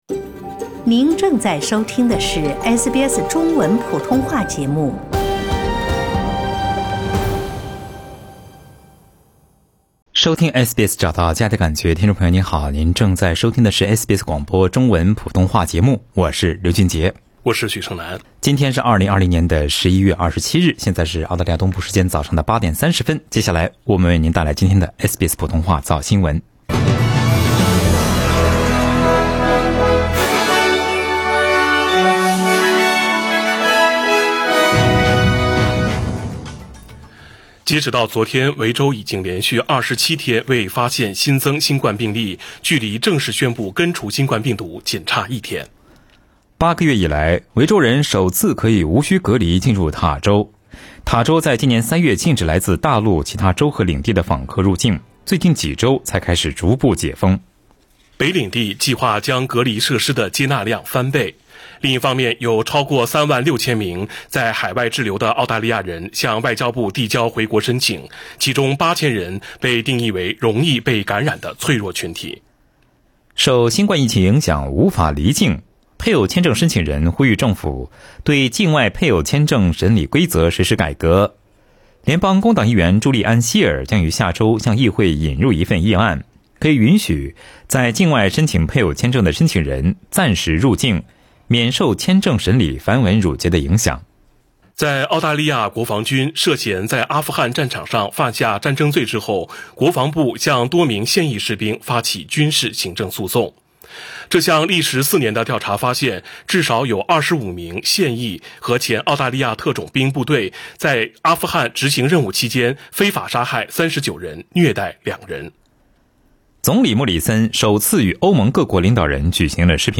SBS早新聞（11月27日）